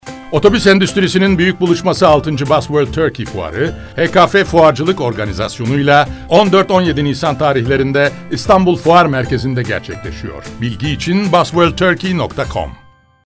Radyo Spotlarımızdan Örnekler